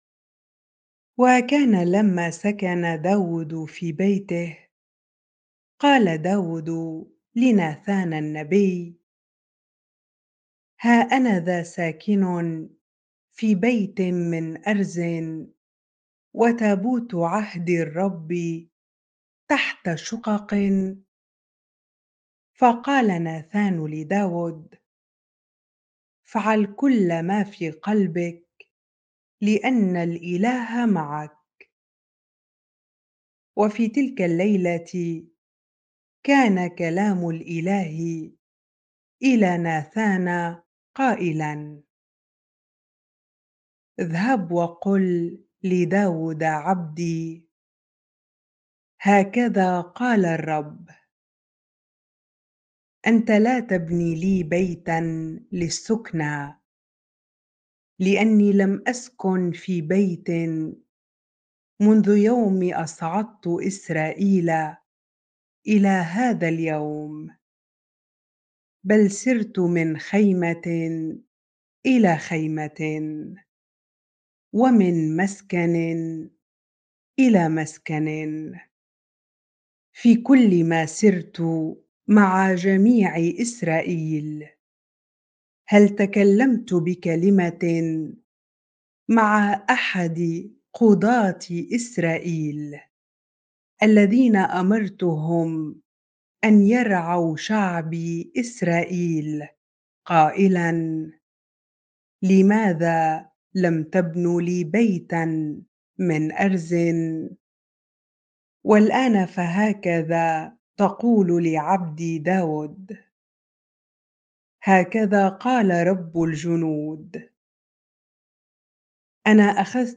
bible-reading-1 Chronicles 17 ar